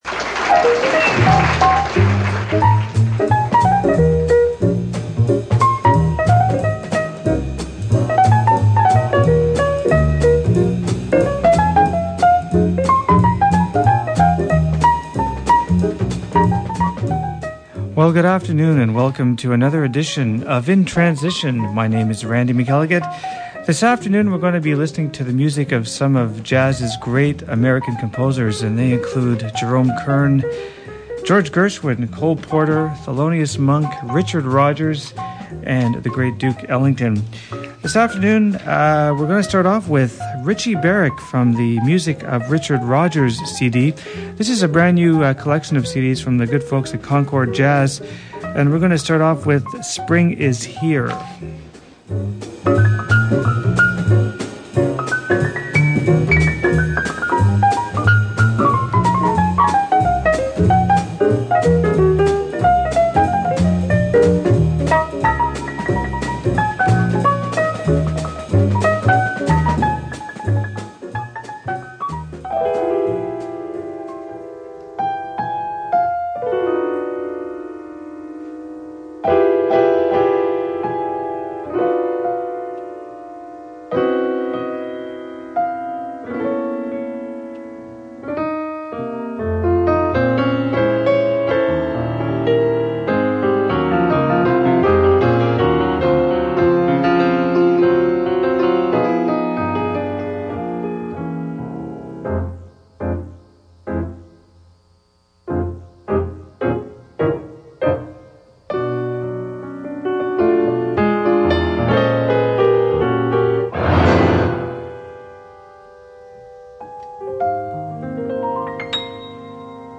Today's episode is full of great jazz!